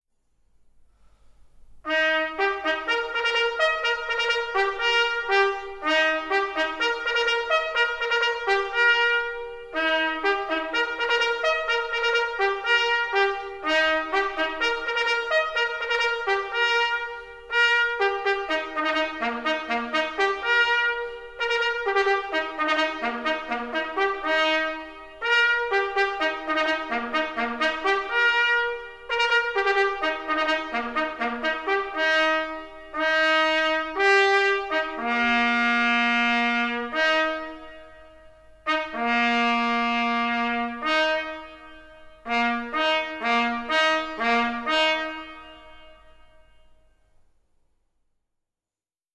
Signaal-Taptoe-Bereden-Wapenen.mp3